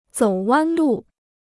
走弯路 (zǒu wān lù) Free Chinese Dictionary